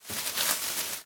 bushes2.ogg